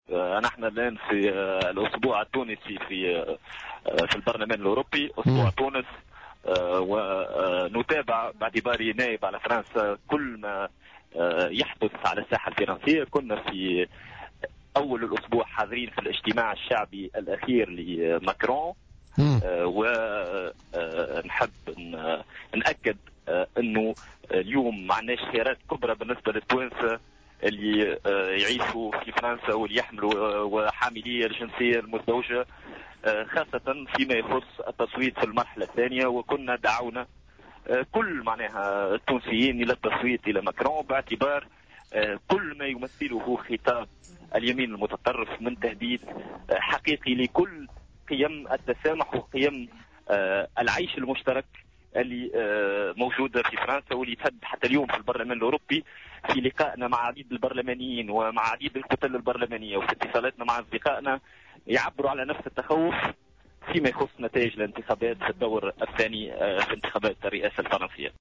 وأضاف فلفال خلال مداخلته في برنامج "بوليتيكا" اليوم الخميس، إنه لا خيار أمام التونسيين في فرنسا سوى التصويت لماكرون، لأن مرشحة اليمين المتطرف تعتبر تهديدا حقيقيا لقيم التسامح والعيش المشترك، مشيرا إلى أن الكتل البرلمانية الأوروبية عبرت كذلك عن تخوفها من فوز لوبان مقابل دعمها لماكرون.